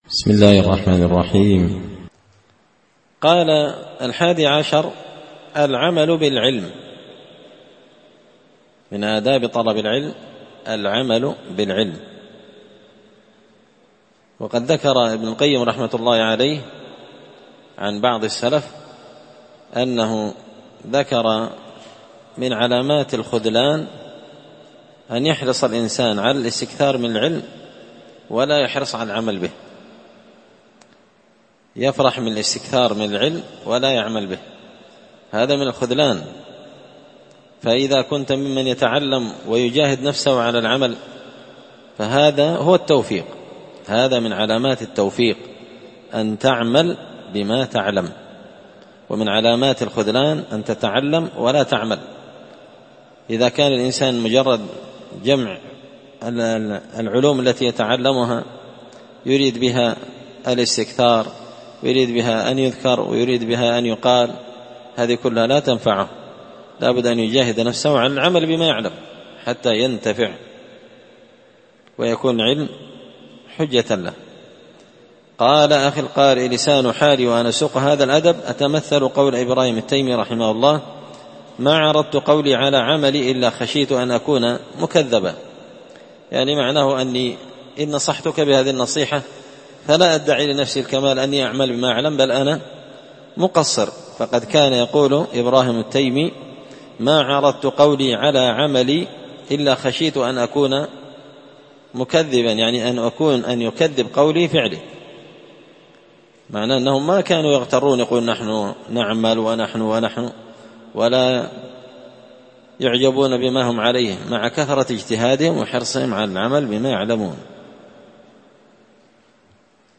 الدرس الخامس عشر (15) الأدب الحادي عشر العمل بالعلم